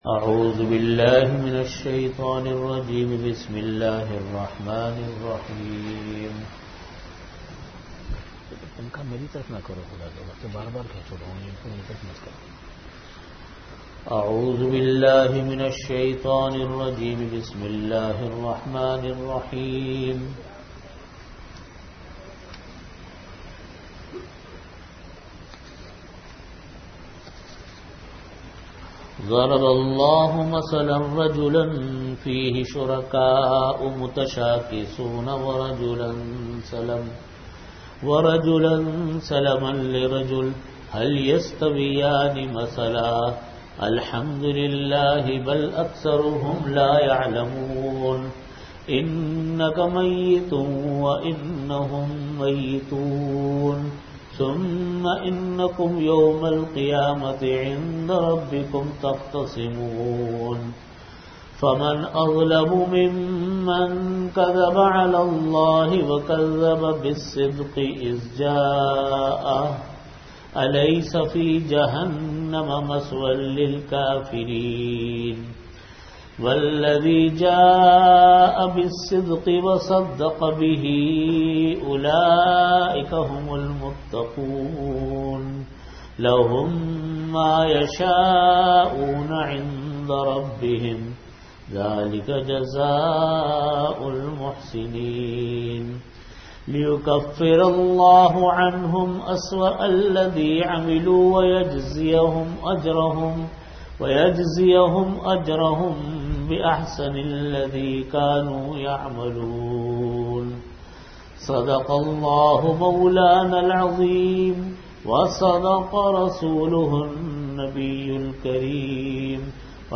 Audio Category: Tafseer
40min Time: After Asar Prayer Venue: Jamia Masjid Bait-ul-Mukkaram, Karachi